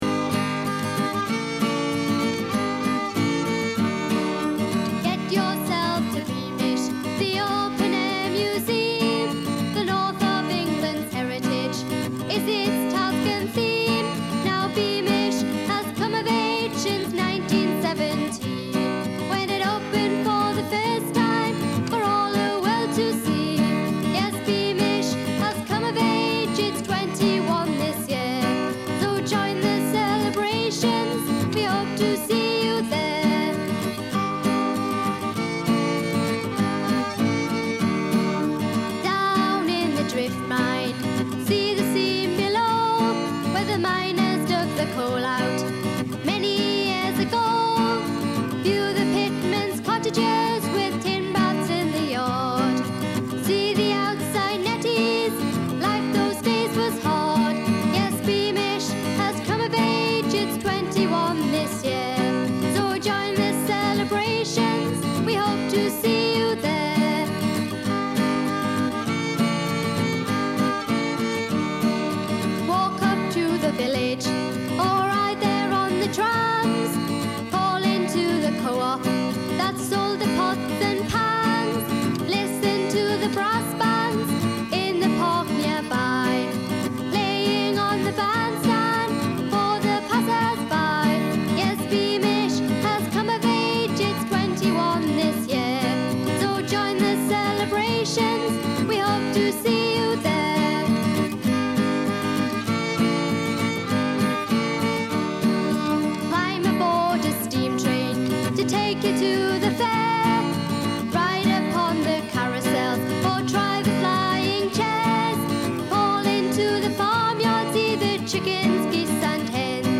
Credits : Recorded at the Red Nose Studios in May 1991.  Vocals
Guitar
Violin
You may also see a theme in these songs of his writing style – very simply rhyming couplets.